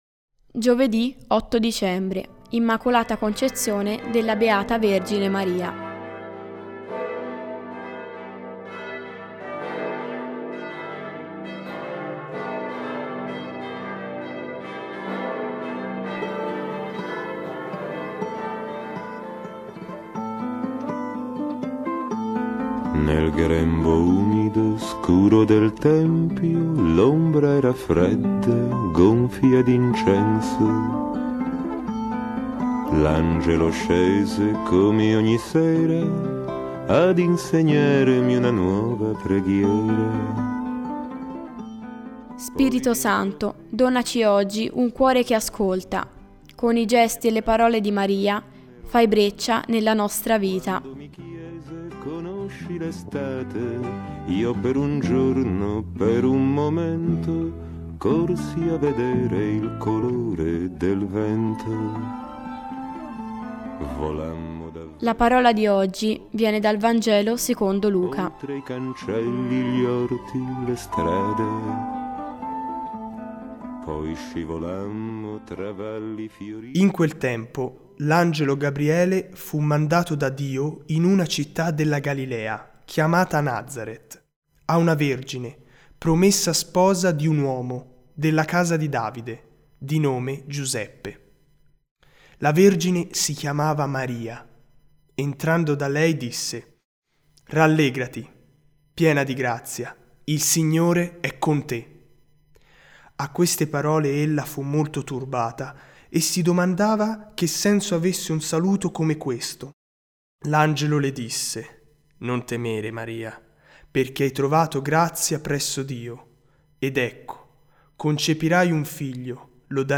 Musica: Il sogno di Maria – De André